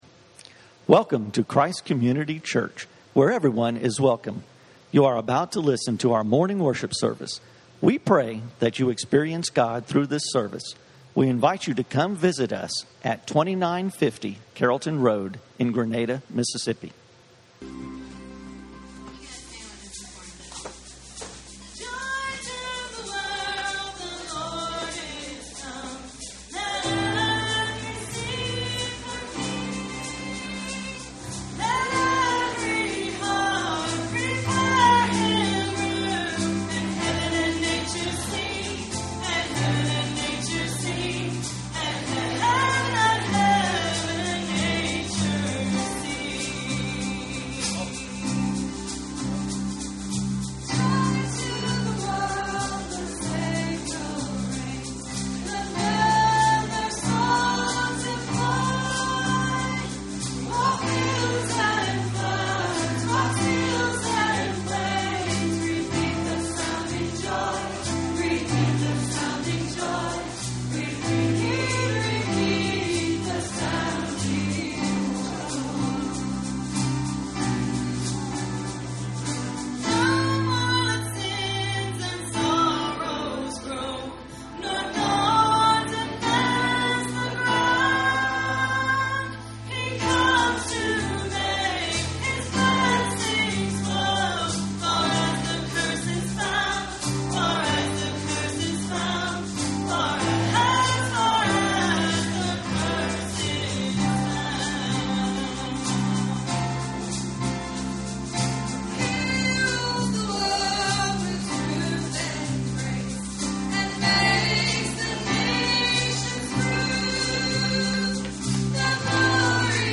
The Coming of a Special Son - Messages from Christ Community Church.